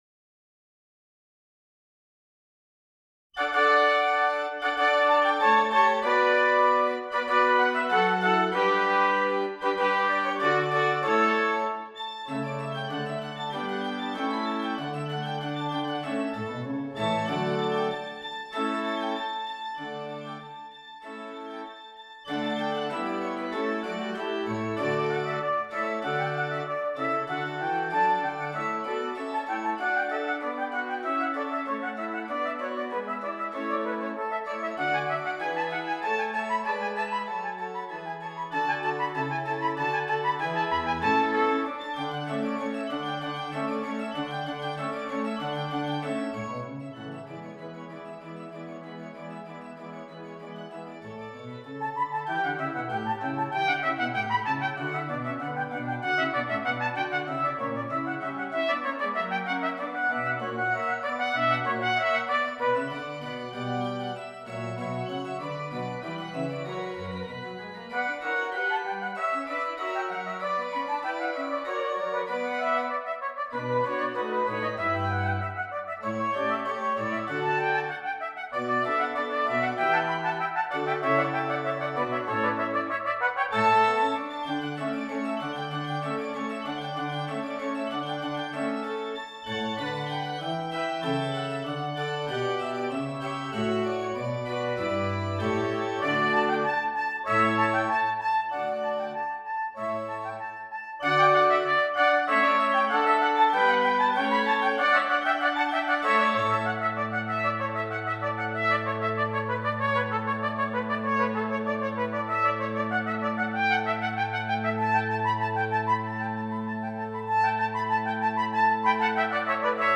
2 Trumpets and Keyboard